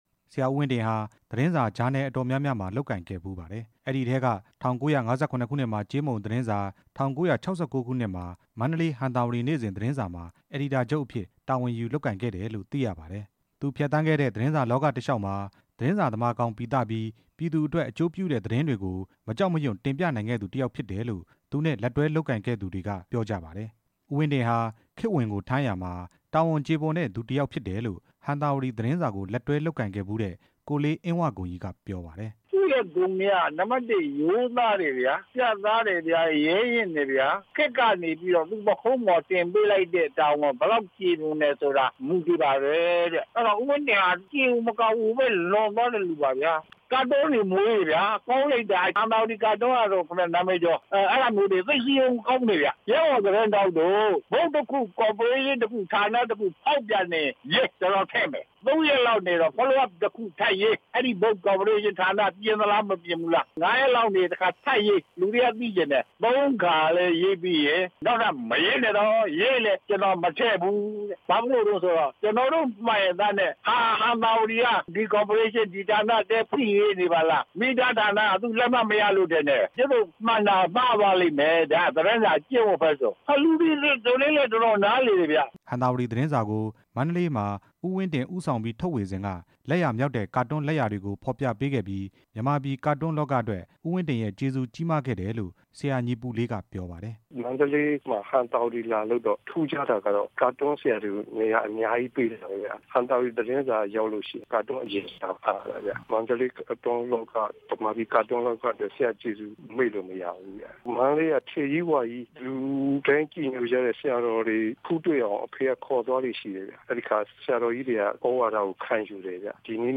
သတင်းစာလောက က လုပ်ဖေါ်ကိုင်ဖက်တွေရဲ့ ပြောပြချက်